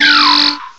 cry_not_tympole.aif